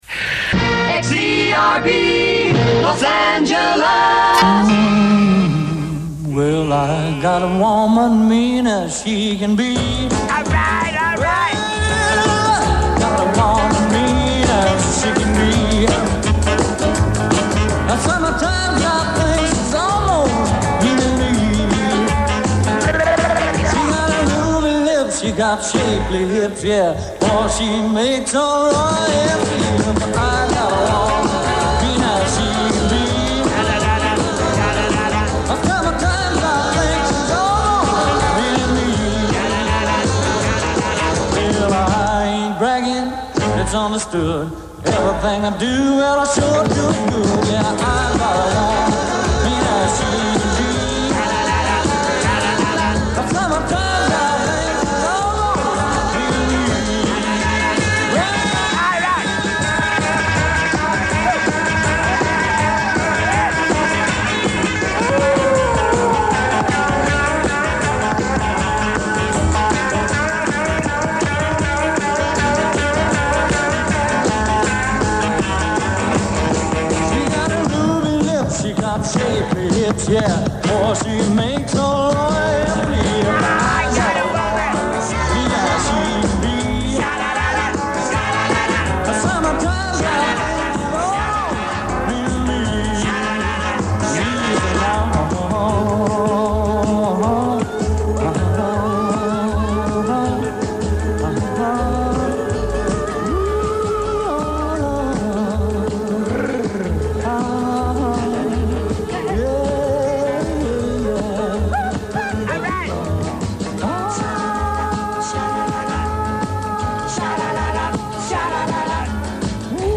Wolfman Jack († 01. Juli 1995) ist auch heute noch der kultigste Radio-DJ aus den USA.
Passend zu seinem Namen und seinem eigentümlichen Erscheinungsbild ertönte wolfsähnliches Geheul zwischen seinen mit Gags gespickten, heiseren Ansagen und den Songs.